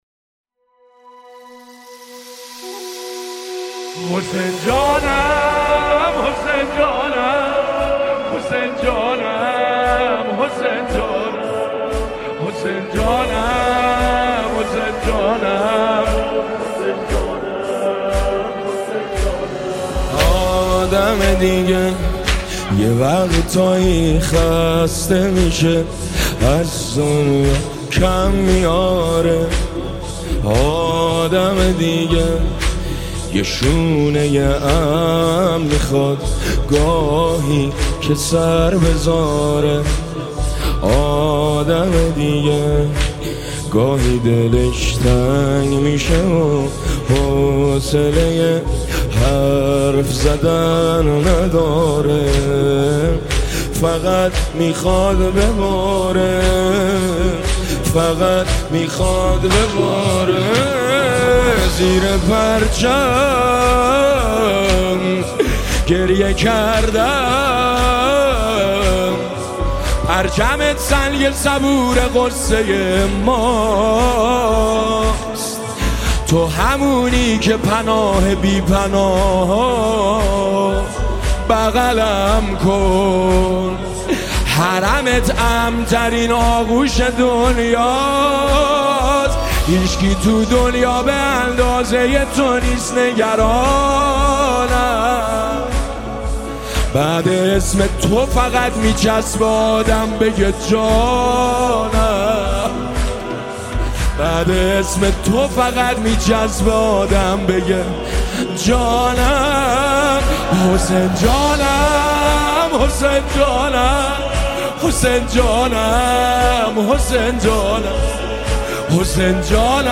با نوای دلنشین